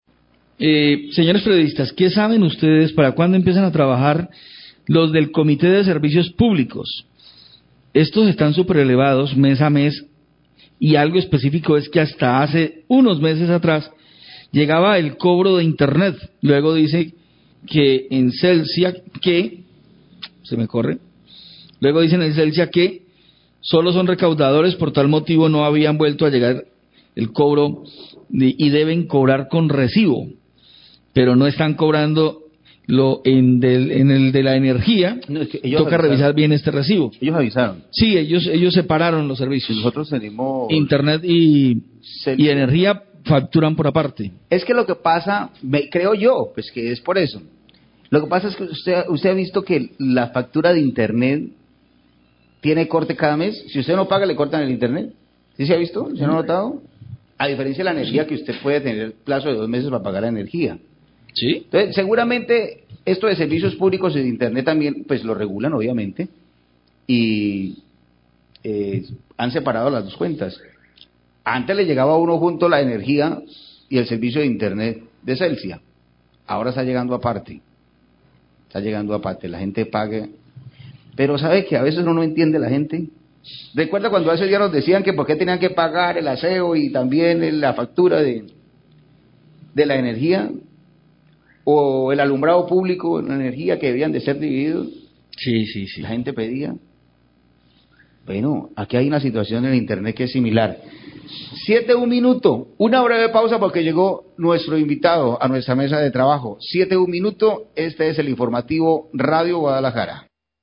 Periodistas aclaran a oyente que Celsia tiene factura aparte para servicio de internet
Radio
Mensaje de oyente por whatsapp donde se pregunta cuándo entra a operar la Comisión de Servicios Público de Buga ya que Celsia obliga a pagar el servicio de internet en la misma factura de energía. Los periodistas le aclaran que Celsia tiene facturación idependiente para cada servicio.